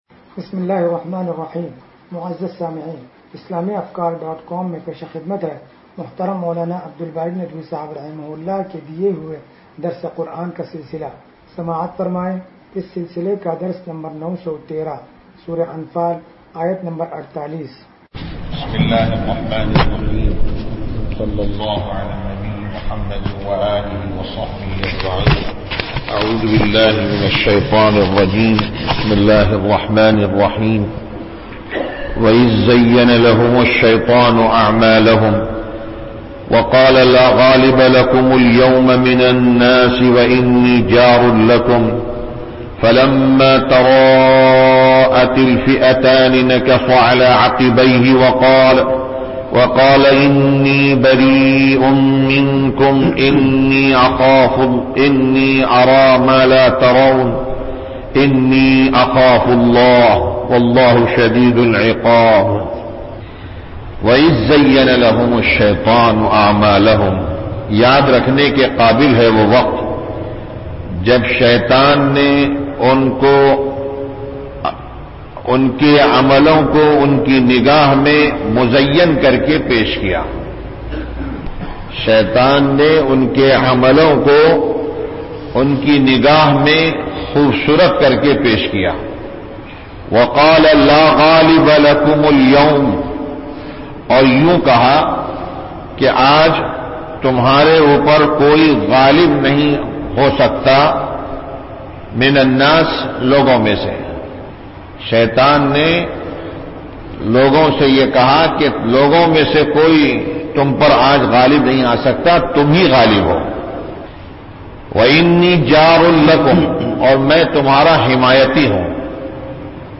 درس قرآن نمبر 0913